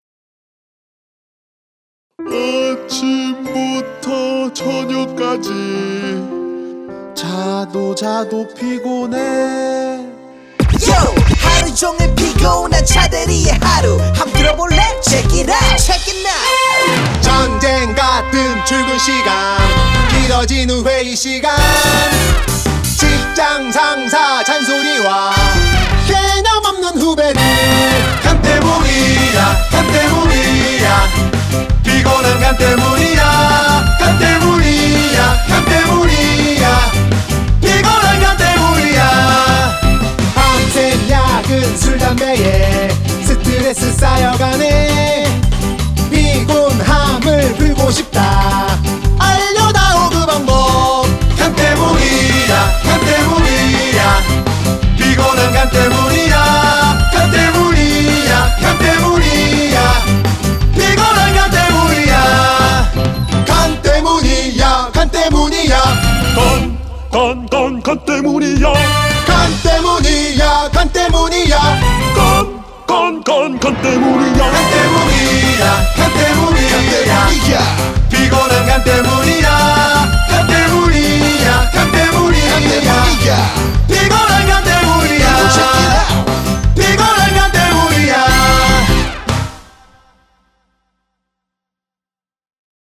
기존 보컬음원에 브라스밴드로 연주가능한 ska곡으로편곡해보았습니다.